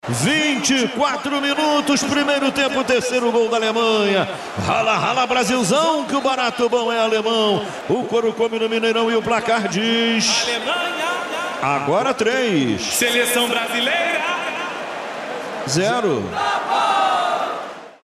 Narração